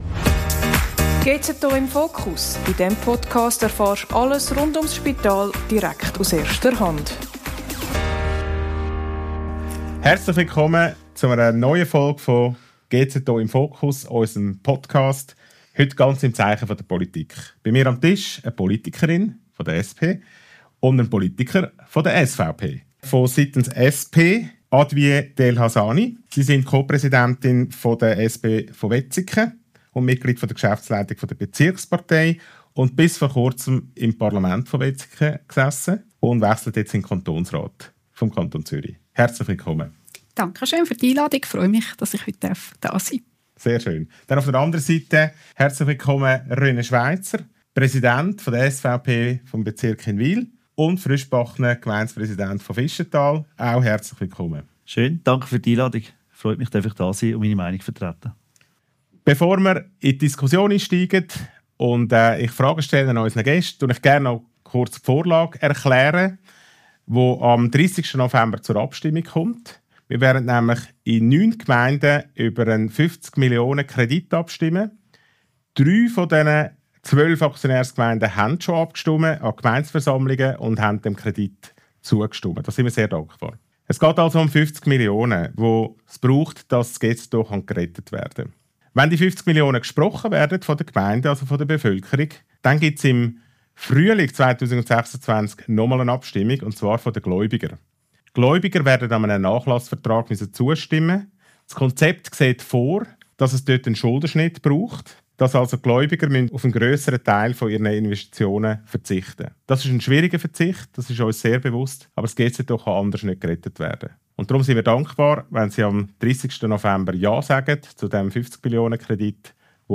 Es zeigt sich: SP und SVP sind sich eben doch nicht überall einig. Hört rein in die spannende Diskussion.